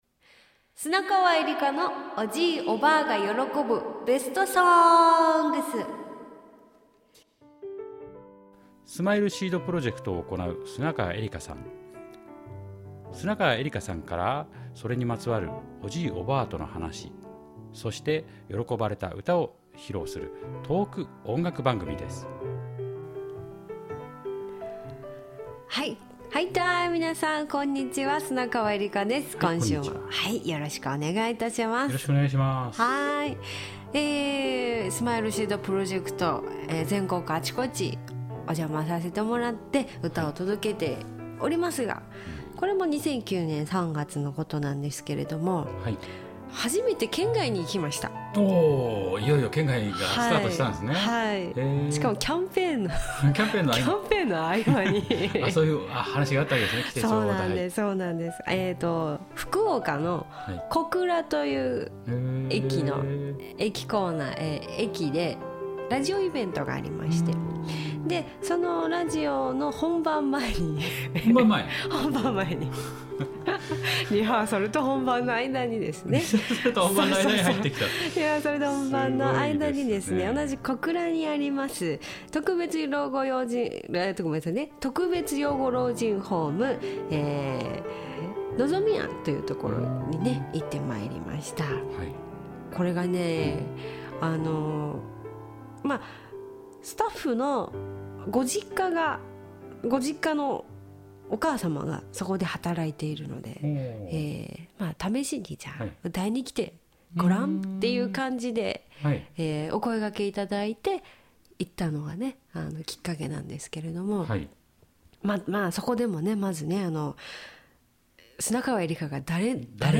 スマイルシードプロジェクトで福岡は小倉の特別養護老人ホームを訪れた砂川恵理歌さん。
自己紹介から始まったステージは１曲目の「籠の鳥」から意外な展開に… 2013年06月10日 公開